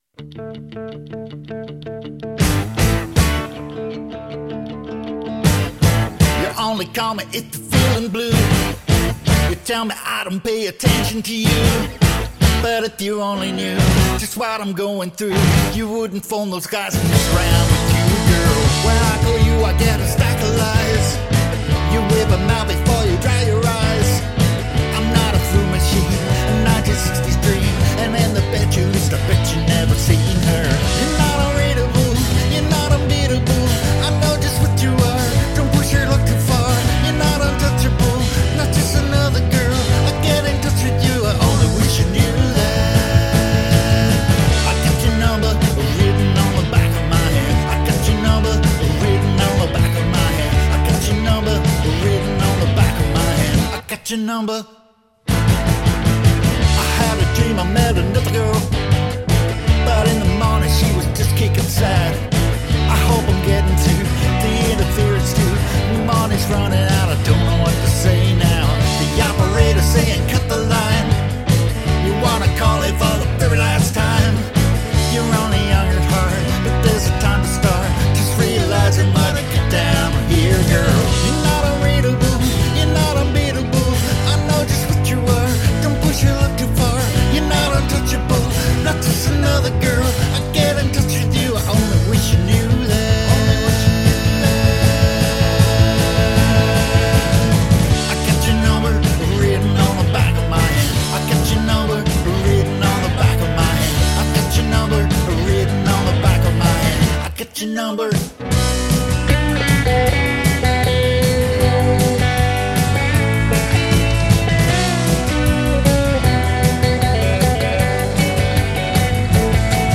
Absolute 80s power-pop banger.